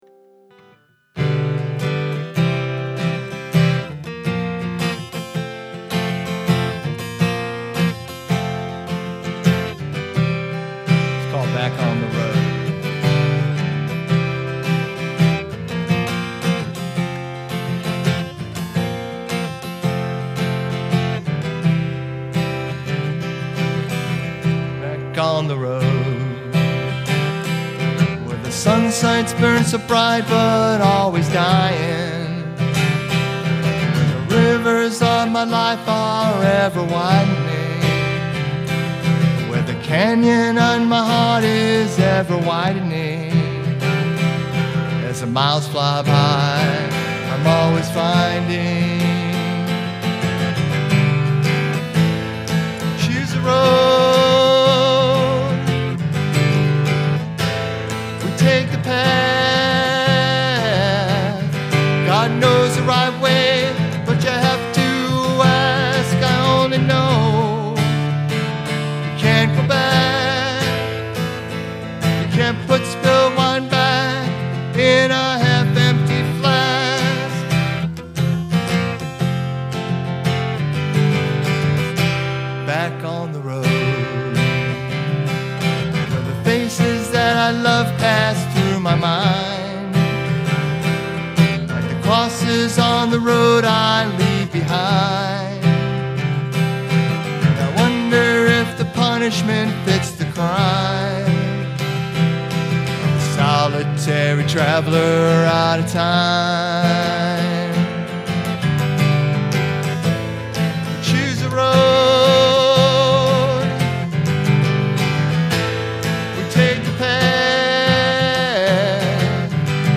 Road-tested Christian blues-rock singer/songwriter